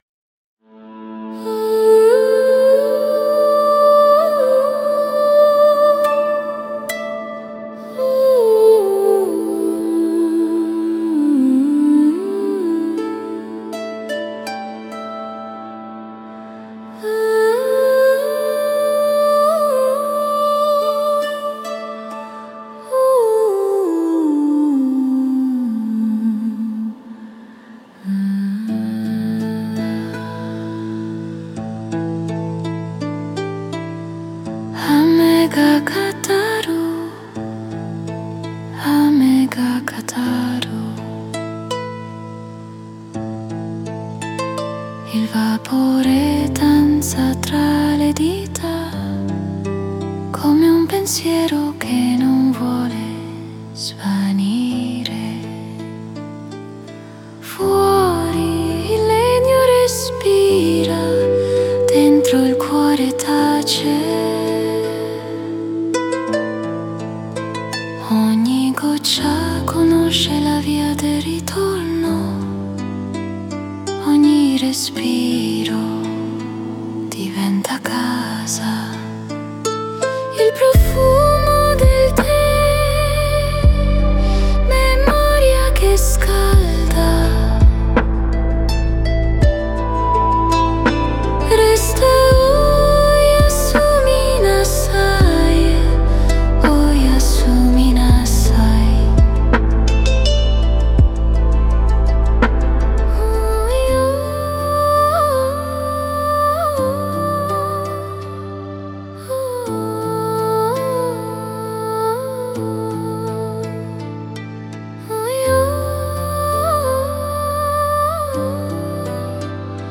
Stanotte, lasciati cullare dal suono più antico del mondo: la pioggia.
Ogni goccia che cade sui tetti e sulle foglie è una sillaba del silenzio.